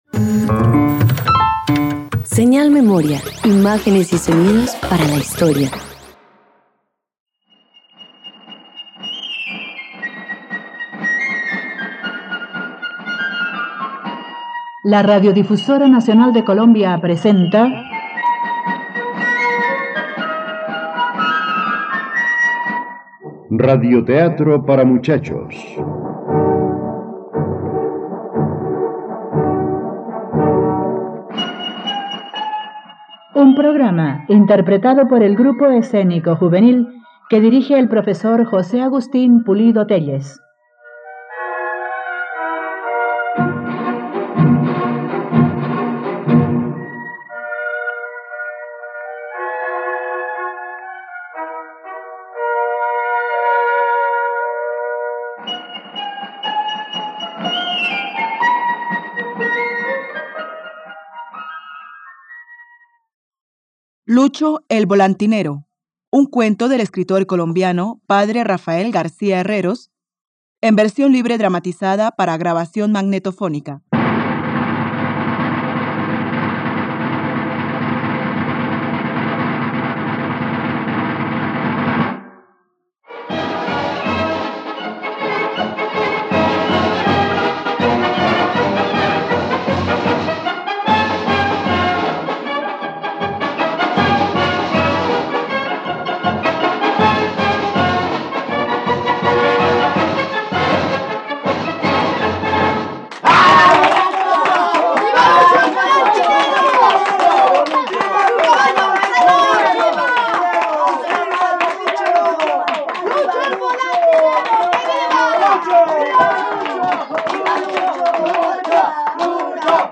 Lucho “El volantinero” - Radioteatro dominical | RTVCPlay